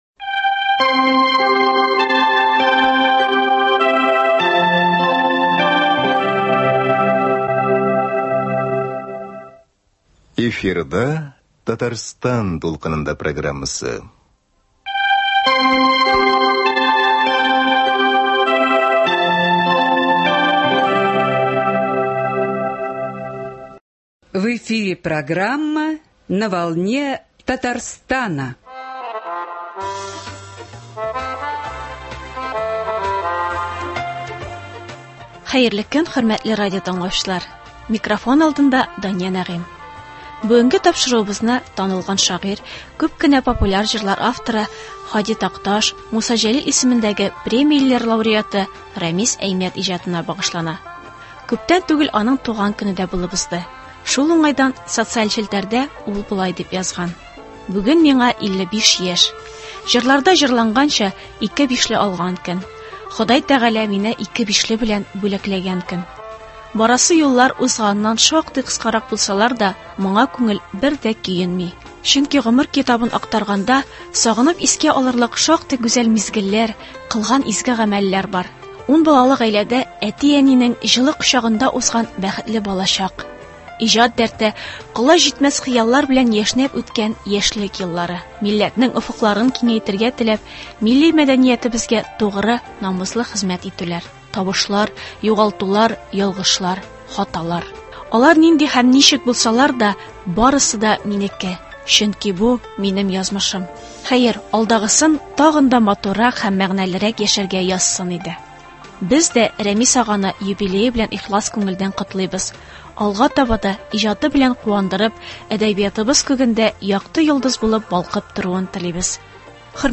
Игътибарыгызга аның әсәрләреннән төзелгән радиокомпозиция тәкъдим итәбез.